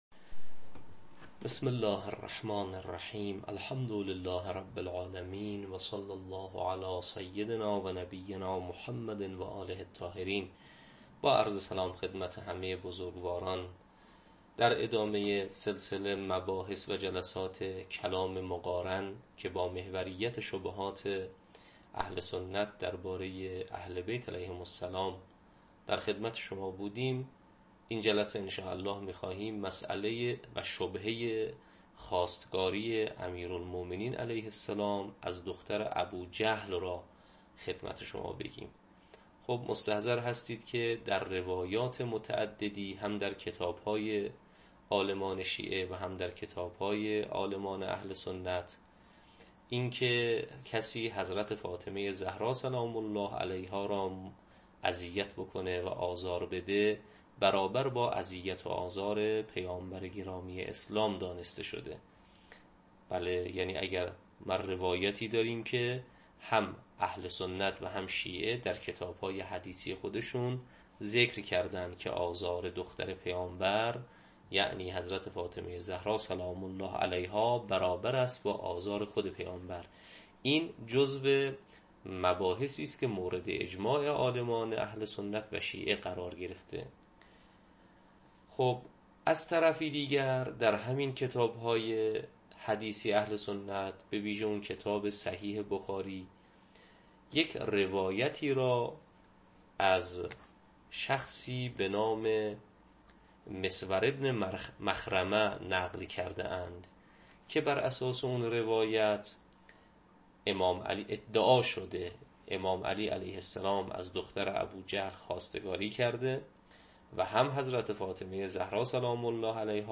تدریس کلام مقارن